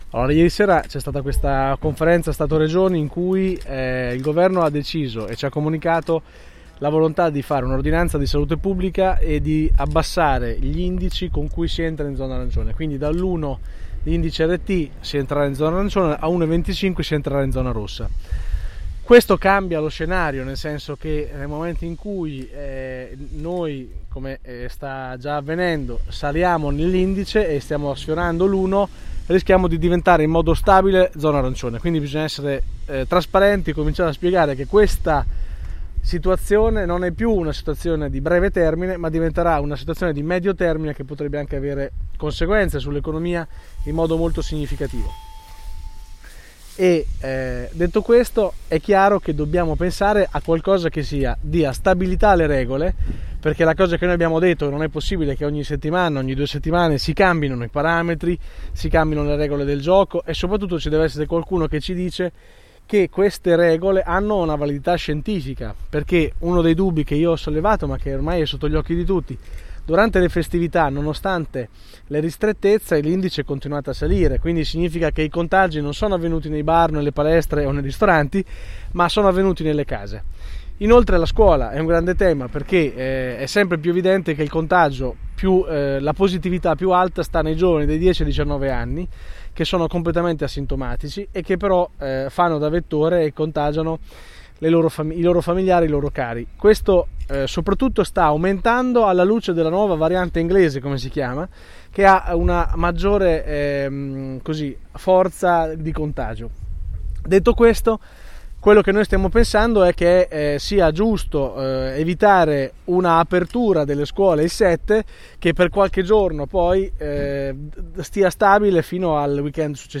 Zona arancione e apertura scuole. Questi i temi affrontati ai nostri microfoni, dal vicepresidente della Giunta Regionale Mirco Carloni che ne ha parlato a margine della conferenza stampa della Cna sul bilancio economico annuale a Pesaro.